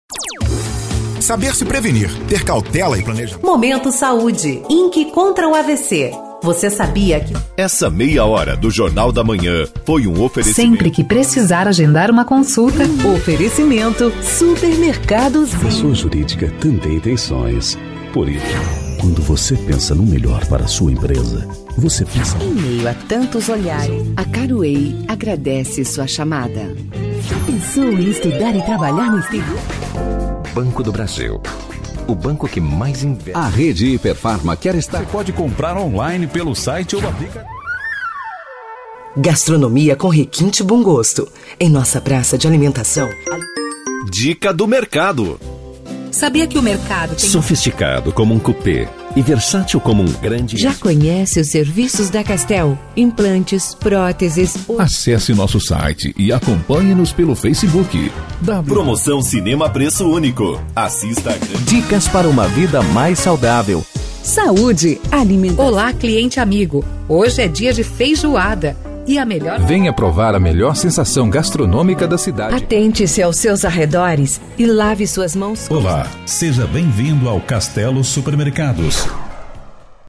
comercial2.mp3